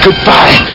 Amiga 8-bit Sampled Voice
1 channel
byebye.mp3